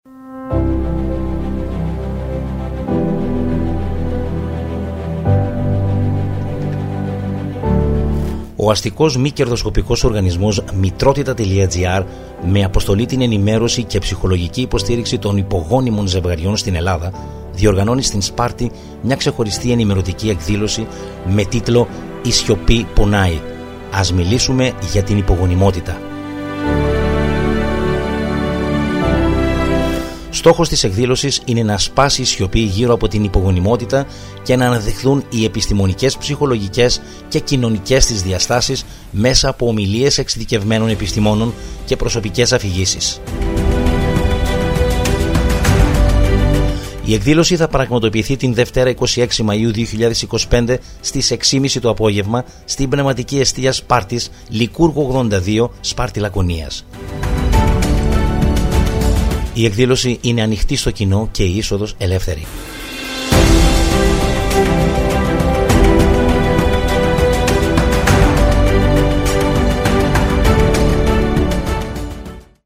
Ραδιοφωνικό Spot